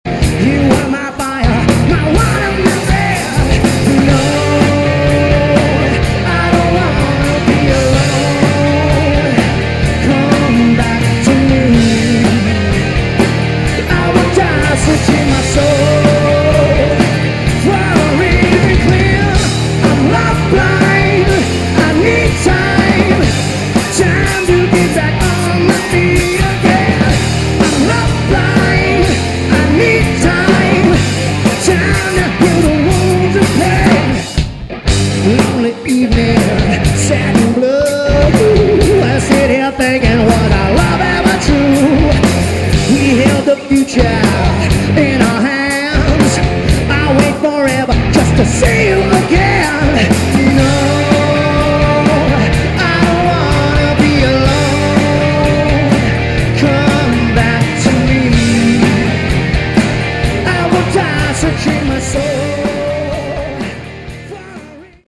Category: Hard Rock
Lead Vocals, Acoustic Guitar
Backing Vocals
Guitar
Bass
Drums